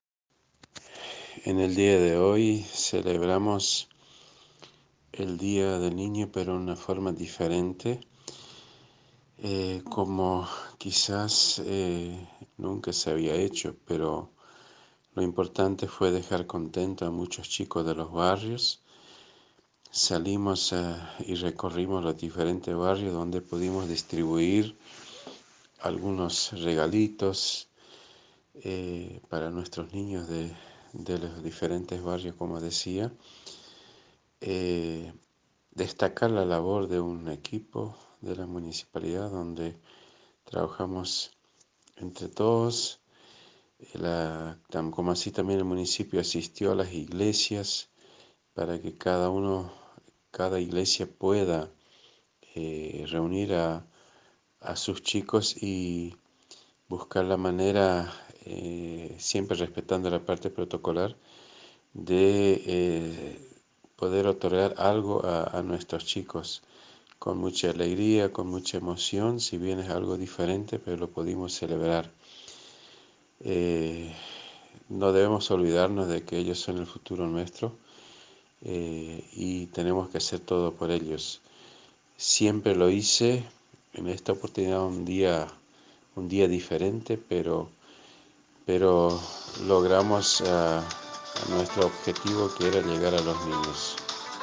Audio de la Intendente Mario Lindemann